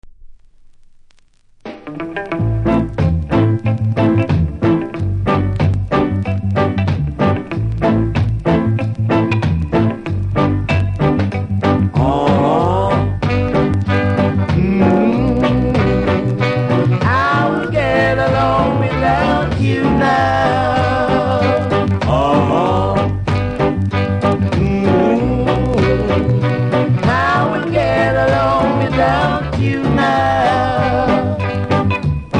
両面キズはそこそこありますがノイズはそれほどでも無いので試聴で確認下さい。